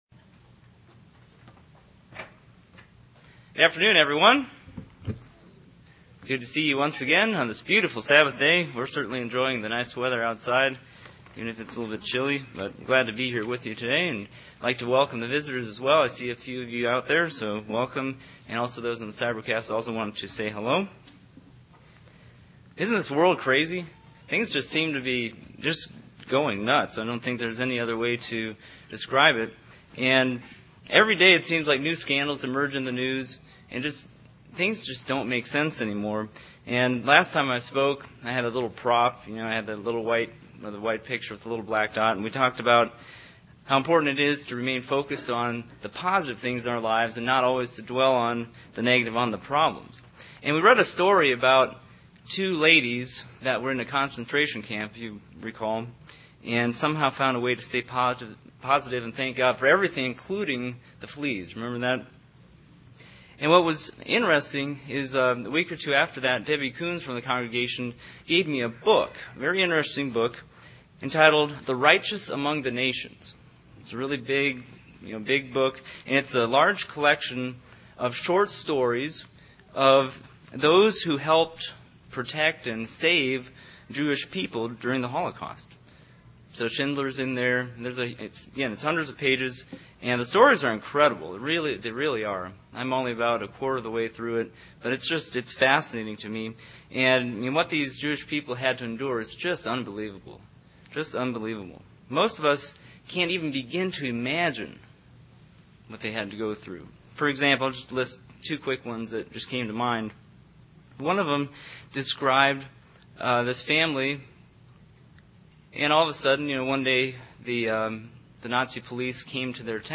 Given in North Canton, OH
UCG Sermon Studying the bible?